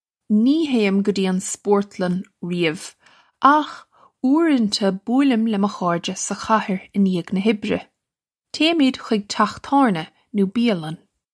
Pronunciation for how to say
Nee hayim guh jee un sport-lun ree-uv, akh oorunta boolim lih muh kharr-ja suh khaher in yayg nuh hibbre. Chaymid khig chokh tawrnya no bee-a-lun.